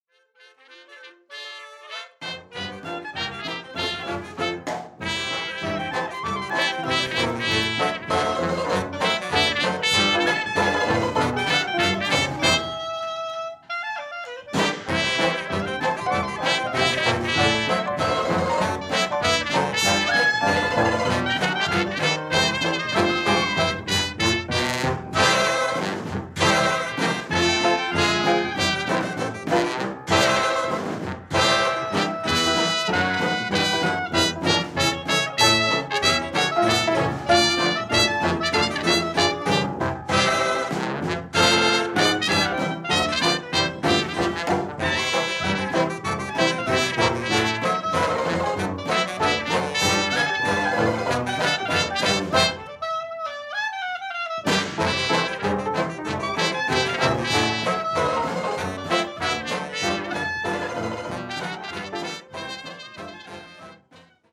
Gatsby Rhythm Kings | Gatsby Era Jazz Band | 1920s Jazz Big Band
Melbourne’s very own 1920’s orchestra.
Gatsby Rhythm Kings Jazz Band are Melbourne's only authentic 1920's Jazz Big Swing band